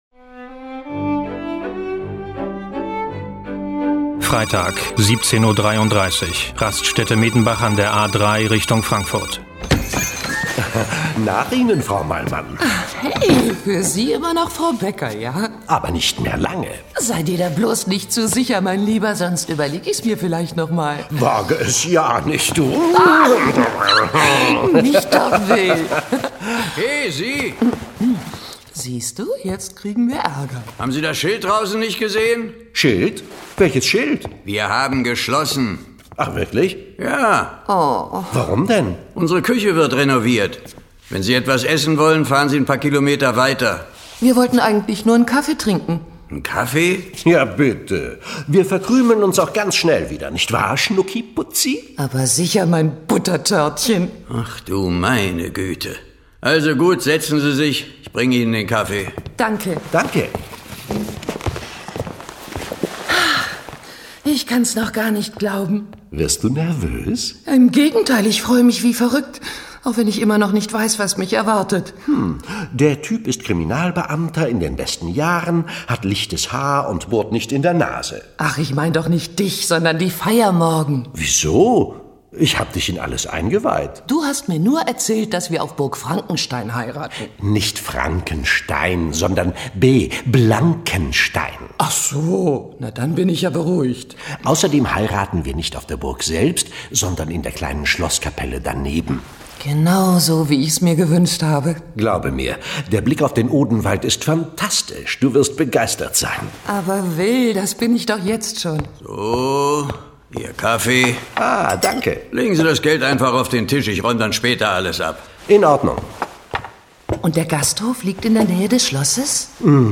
John Sinclair - Folge 19 Der Sensenmann als Hochzeitsgast. Hörspiel.